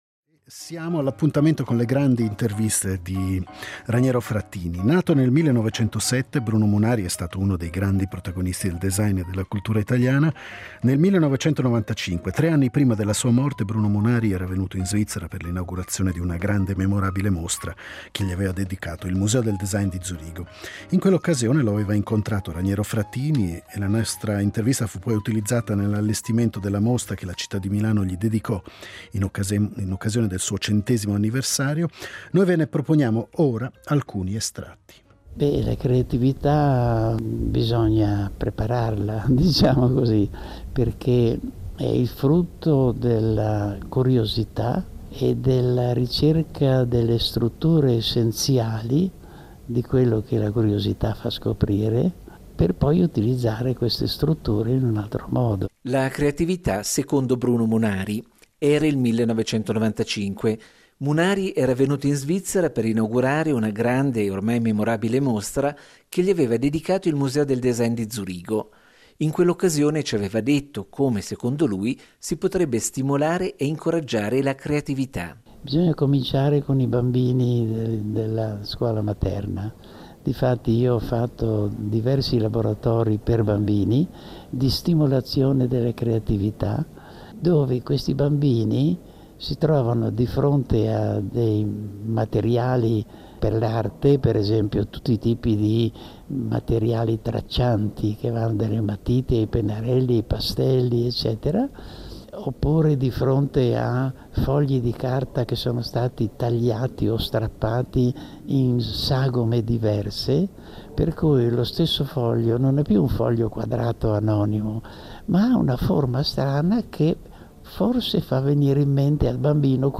Diderot vi propone una serie di interviste che provengono dai nostri archivi.